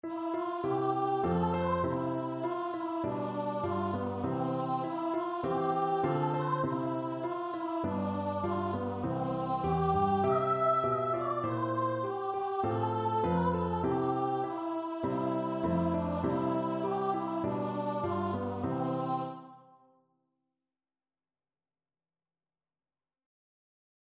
Voice
C major (Sounding Pitch) (View more C major Music for Voice )
4/4 (View more 4/4 Music)
Traditional (View more Traditional Voice Music)
wildwood_flower_VOICE.mp3